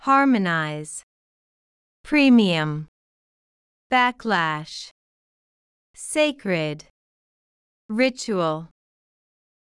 harmonize /ˈhɑːrməˌnaɪz/（動）調和させる、一致させる
premium /ˈpriːmiəm/（名）割増料金、高級品（形）高級な、上質な
backlash /ˈbækˌlæʃ/（名）反発、反動
sacred /ˈseɪkrɪd/（形）神聖な、尊い
ritual /ˈrɪtʃuəl/（名）儀式、習慣的行為（形）儀式の、慣習的な